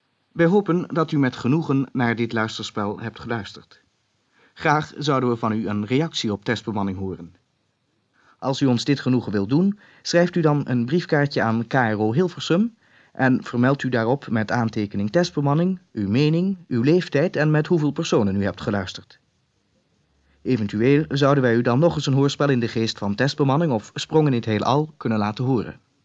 Na de uitzending van het laatste deel van de serie hoorspelen Testbemanning, die sinds 1 oktober van het vorig jaar in 29 uitzendingen voor de microfoon kwam, heeft de KRO-omroeper de luisteraars gevraagd hun mening over deze spelen op papier te zetten, dit met vermelding van leeftijd en het aantal personen dat regelmatig naar het programma had geluisterd.